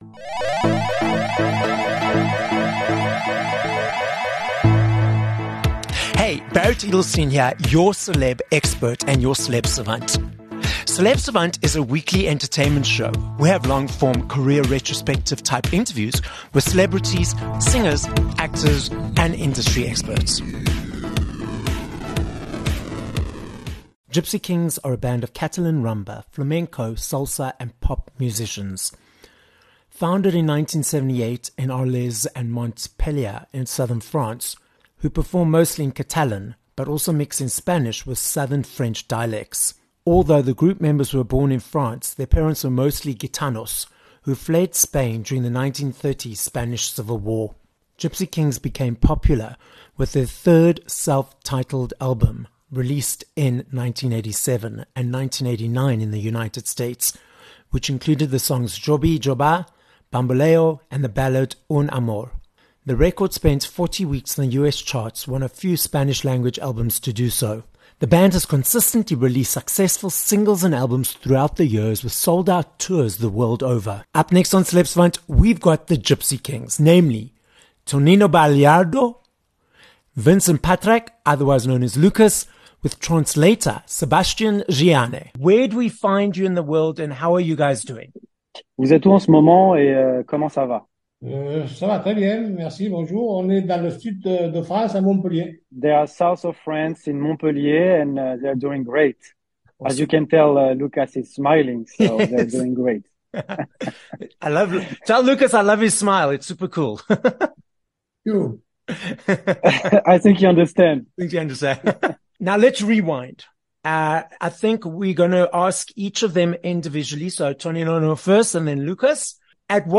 11 Oct Interview with Tonino Baliardo (Gipsy Kings)